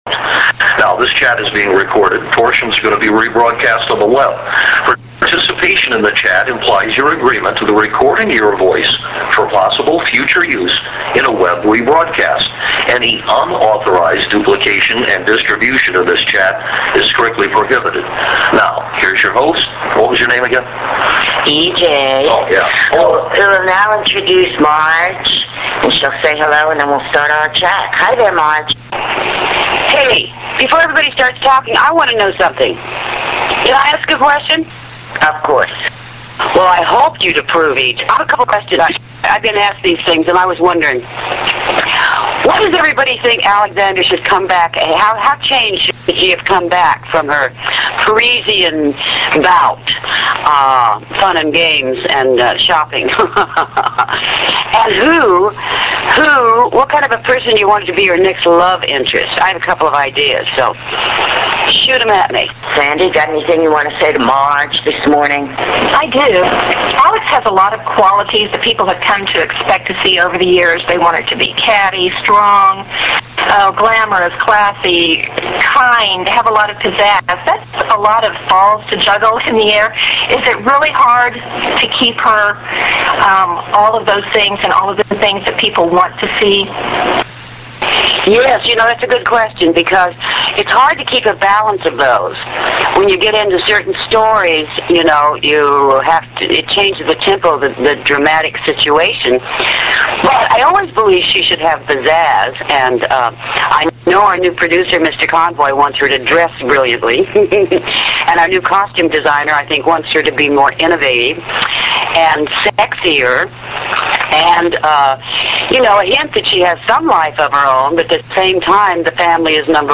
Online Voice Chat